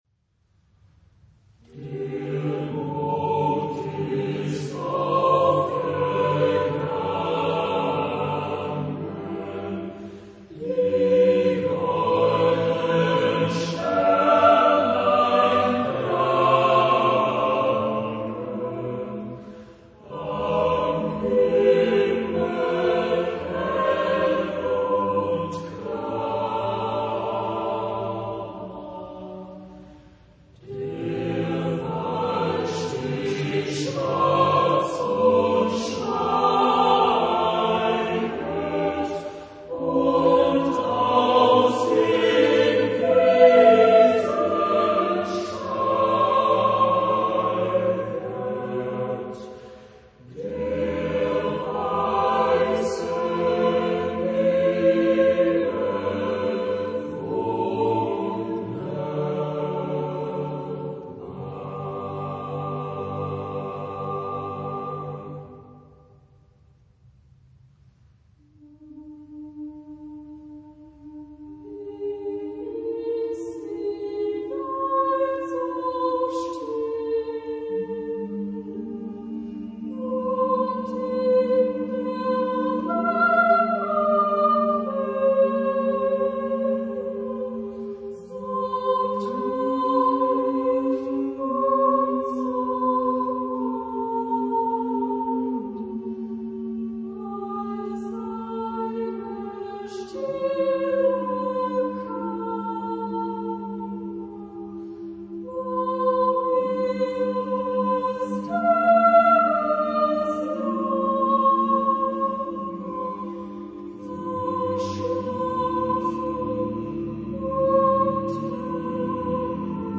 Género/Estilo/Forma: Canción ; Folklore ; Sagrado
Tipo de formación coral: SATB  (4 voces Coro mixto )
Tonalidad : sol mayor